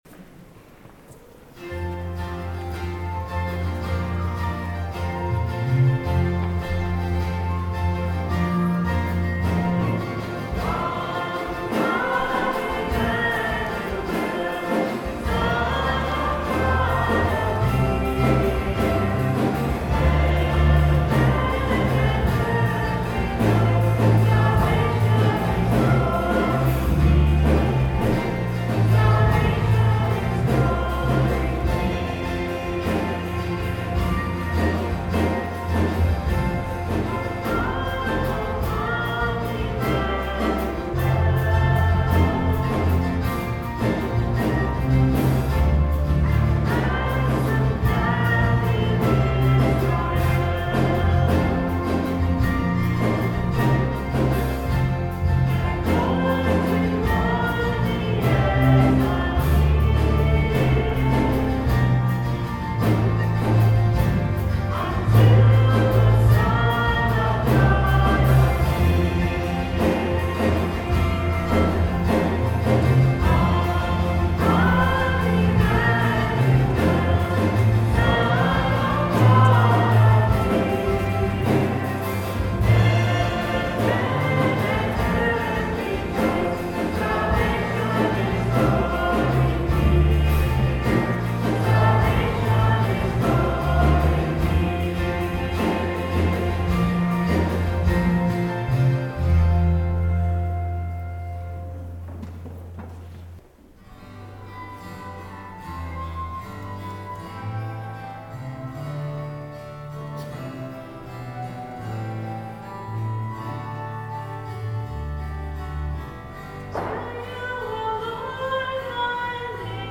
12/20/09 10:30 Mass Recording of Music - BK1030